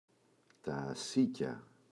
ασίκια, τα [a’sica]